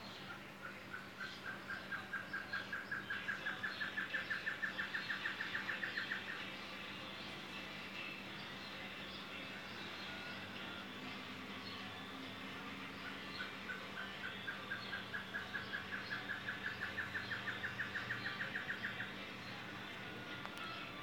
Great Antshrike (Taraba major)
Condition: Wild
Certainty: Recorded vocal